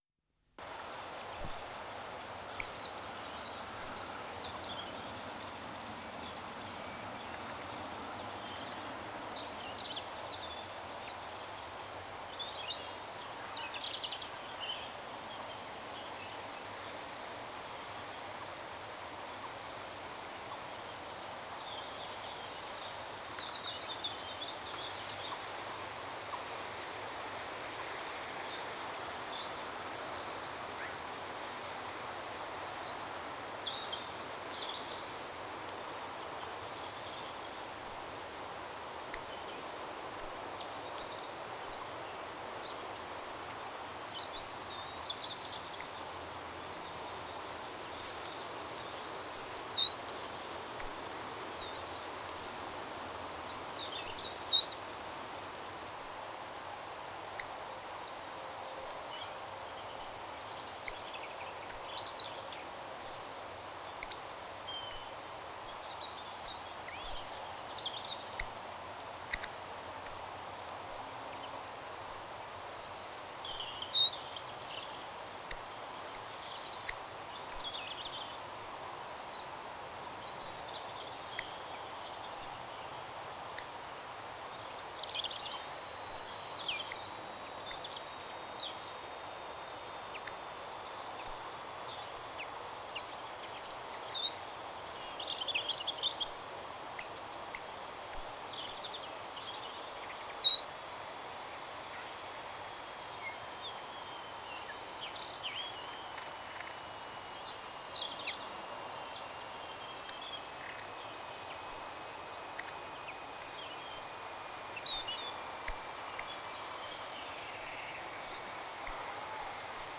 Kernbeißer Ruf
Sein Ruf ist ein kurzes, scharfes „zick“, oft schwer hörbar.
Kernbeisser-Gesang-Voegel-in-Europa.wav